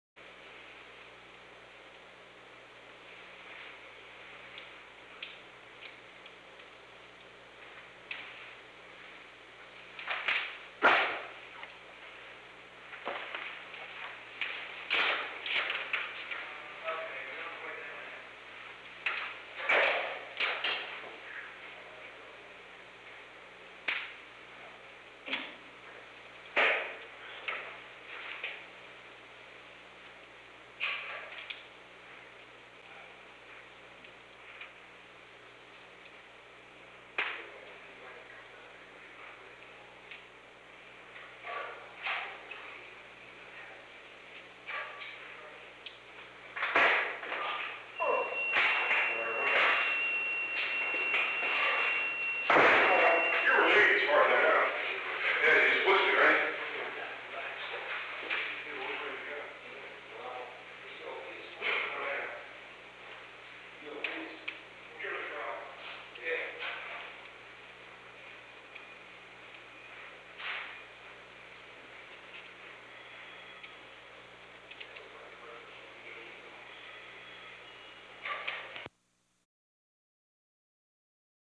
Conversation: 857-014
United States Secret Service agents
Recording Device: Oval Office
The Oval Office taping system captured this recording, which is known as Conversation 857-014 of the White House Tapes.